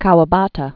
(käwə-bätə), Yasunari 1899-1972.